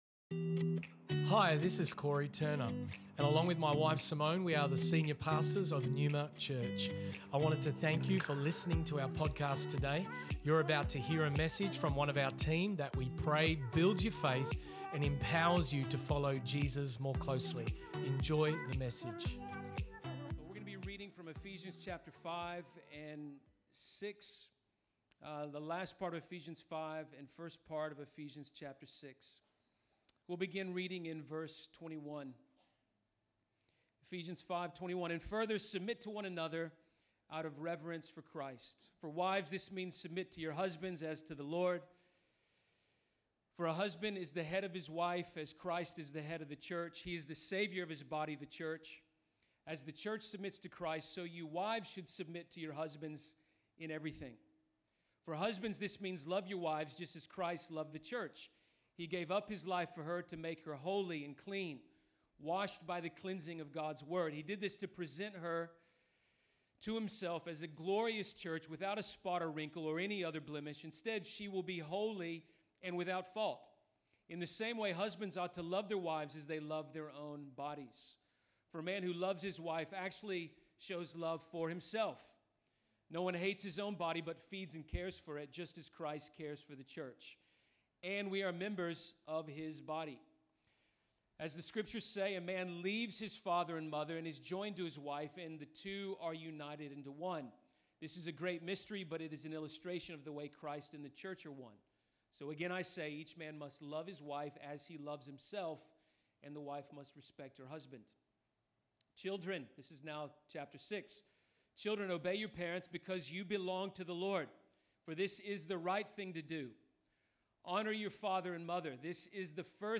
This message was preached on Father's Day, on 5 September, 2021 at NEUMA Church Perth.